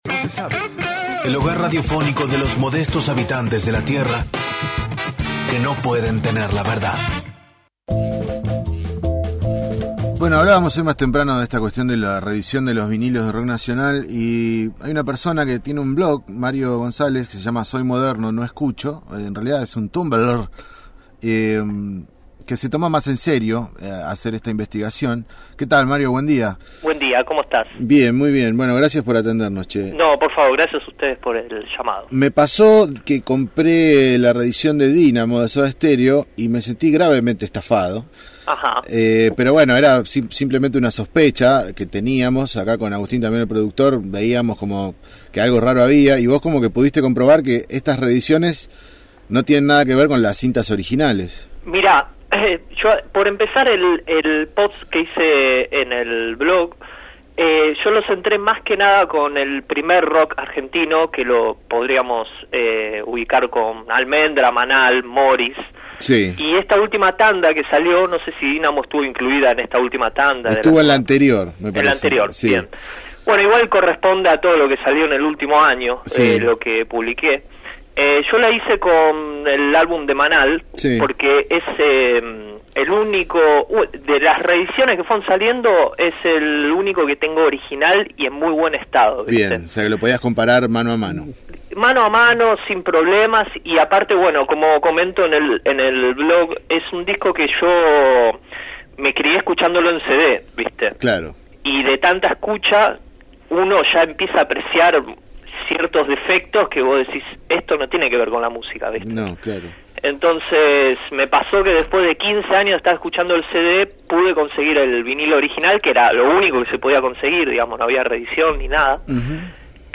dialogó con el equipo de «No se sabe» sobre la reedición de música en discos de vinilo y analizó el fenómeno cultural.